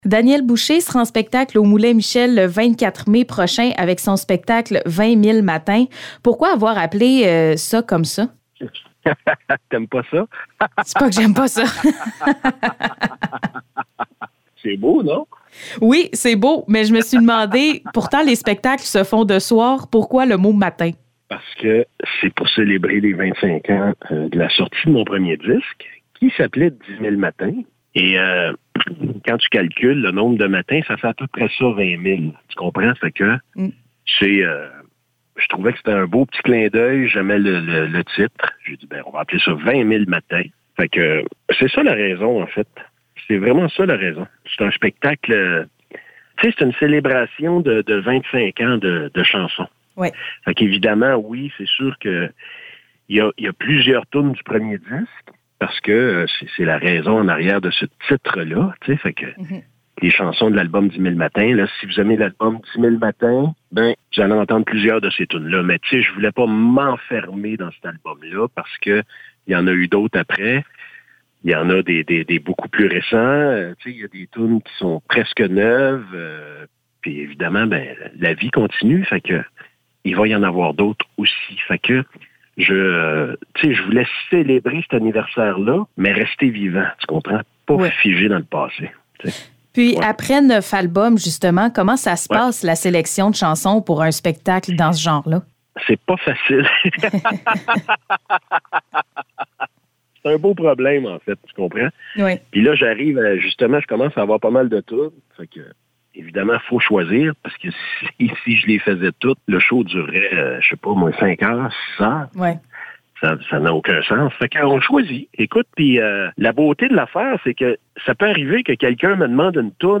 Entrevue avec Daniel Boucher